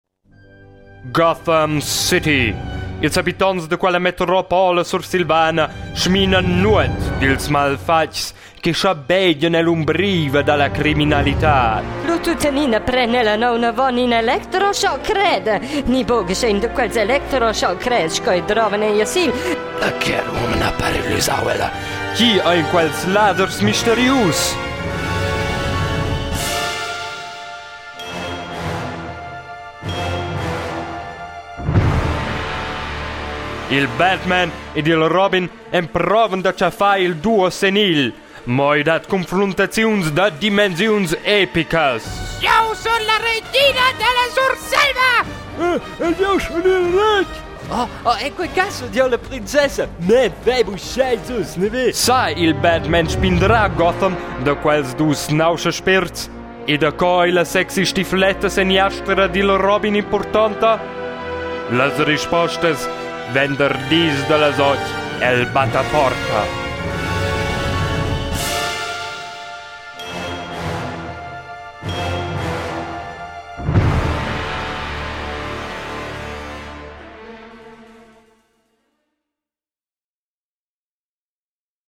Trailer
2 Giugs auditivs sin in disc.
Cullas vuschs tudestgas da Harvey Keitel, Pierce Brosnan e schiglioc aunc empau.